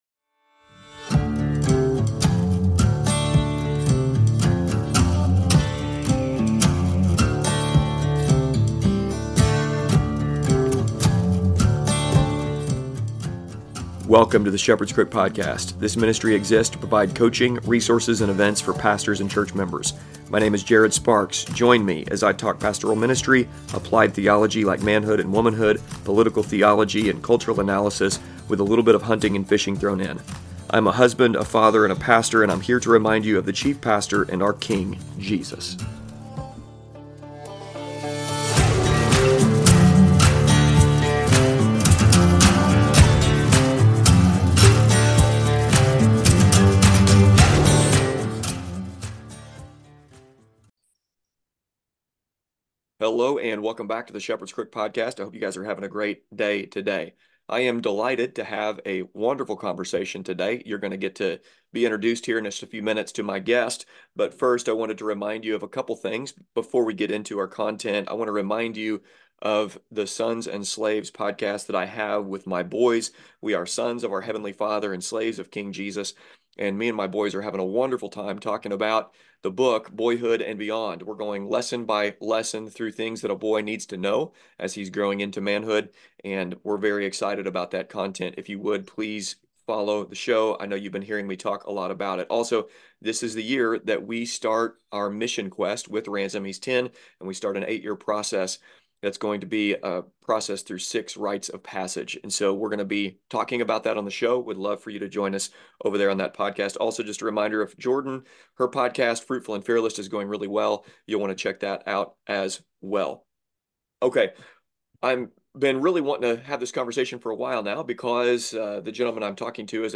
This conversation was a lot of fun.